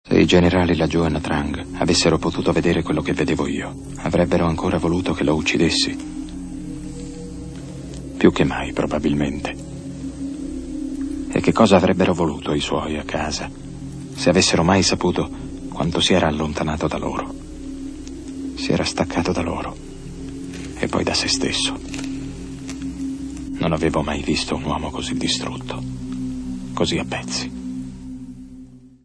voce di Pino Colizzi nel film "Apocalypse Now", in cui doppia Martin Sheen.